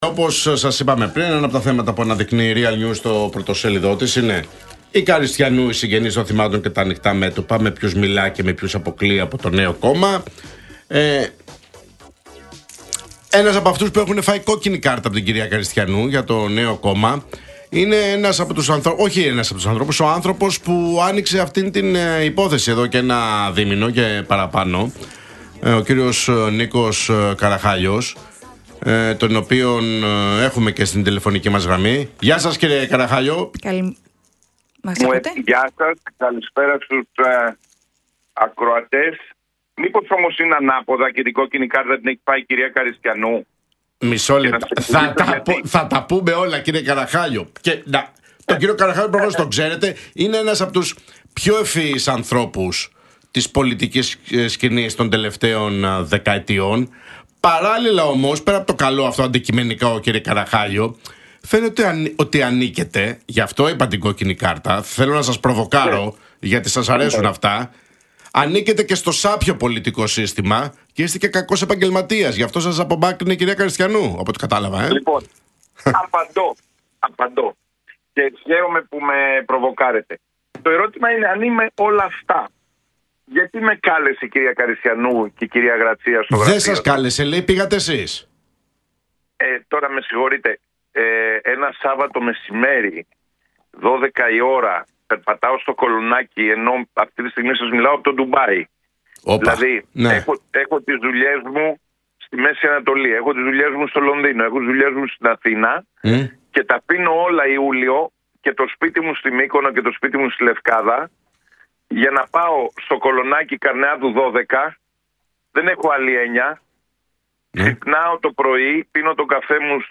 μιλώντας στον Realfm 97,8